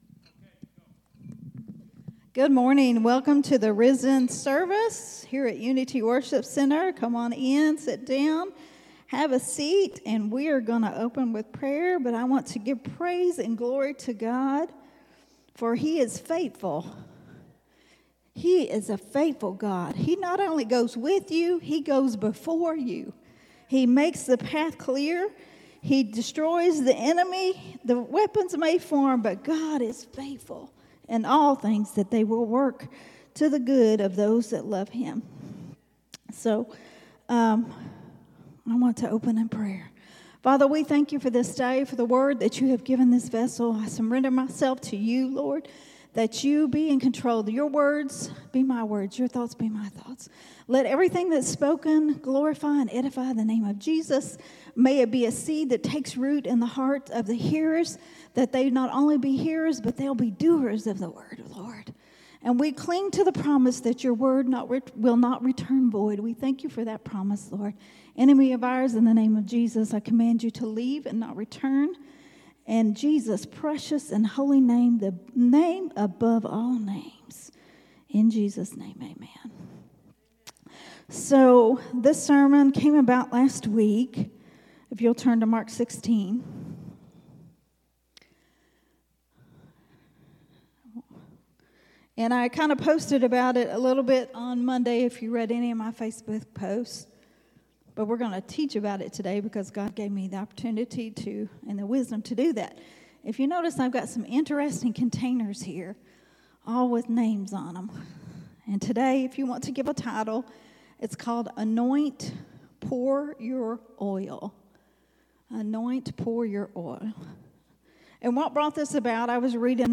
a Sunday Morning Risen Life teaching
recorded at Unity Worship Center on Sunday